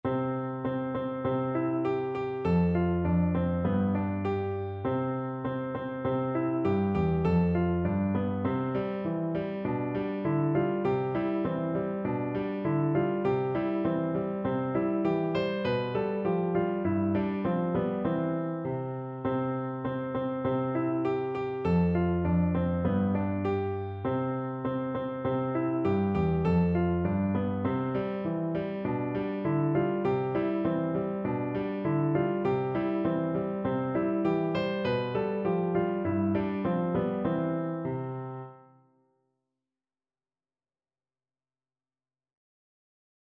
Traditional Trad. Early One Morning Piano version
No parts available for this pieces as it is for solo piano.
2/2 (View more 2/2 Music)
C major (Sounding Pitch) (View more C major Music for Piano )
Verse Two in a bar =c.100
Piano  (View more Easy Piano Music)
Traditional (View more Traditional Piano Music)